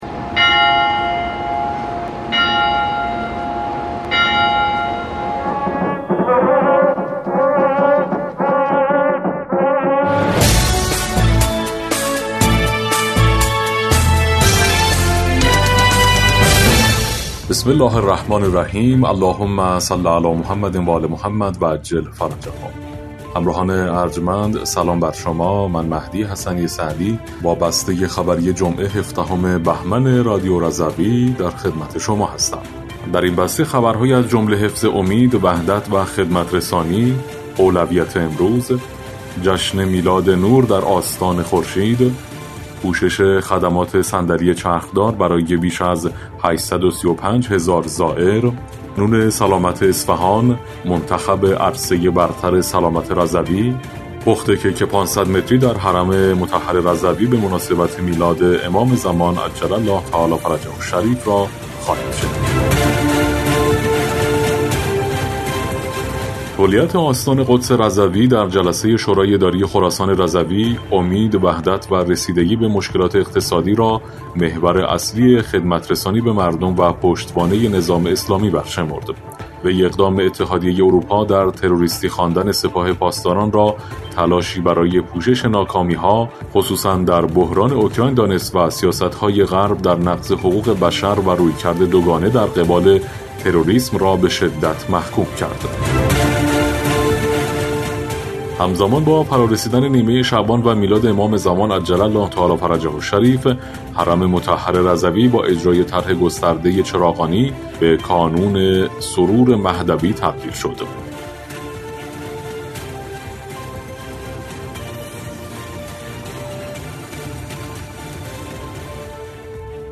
بسته خبری ۱۷ بهمن ۱۴۰۴ رادیو رضوی؛